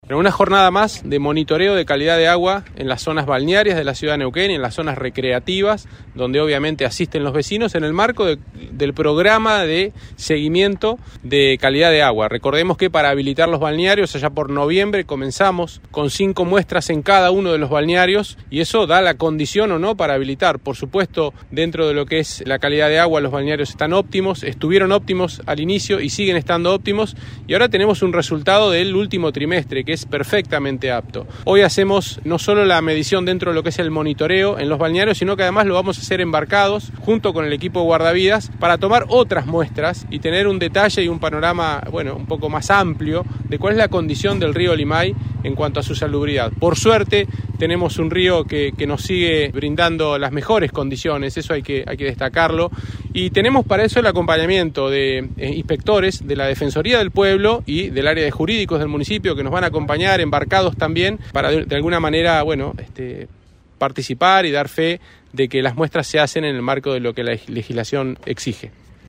Francisco Baggio, subsecretario de Medio Ambiente y Protección Ciudadana.